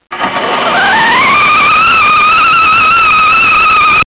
1 channel
00044_Sound_TEA_POT.mp3